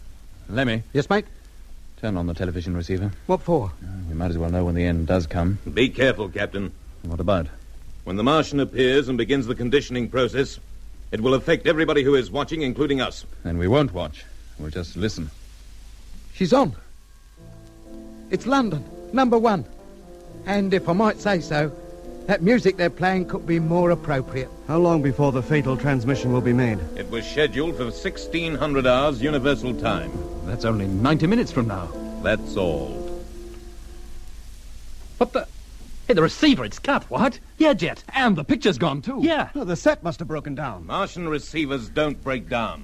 Om te kijken hoe het afloopt, schakelen onze vrienden nog 1 keer de televisie aan. Opvallend dat er in de Britse versie nu wel geluid bij is. Mooi is dan wel weer dat beide versies treurig pianospel weergeven.